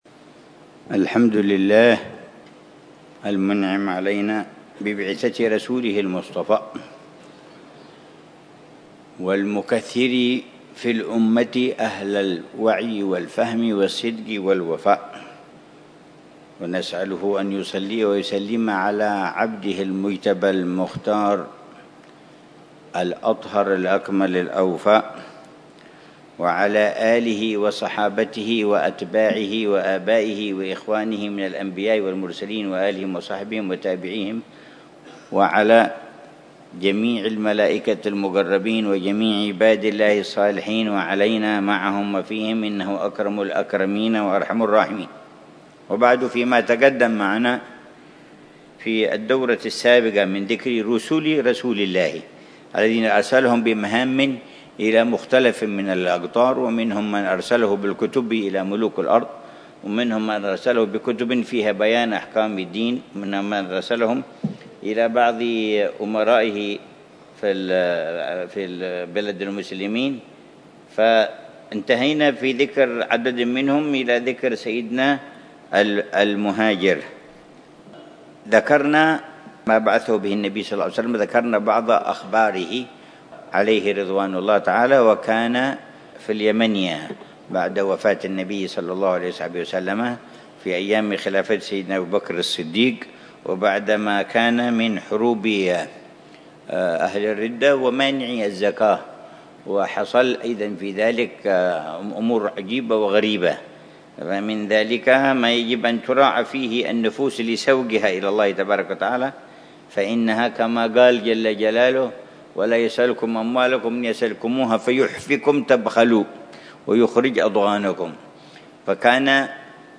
الدرس الأول من دروس السيرة النبوية التي يلقيها العلامة الحبيب عمر بن محمد بن حفيظ، ضمن دروس الدورة التعليمية الثلاثين بدار المصطفى بتريم للدرا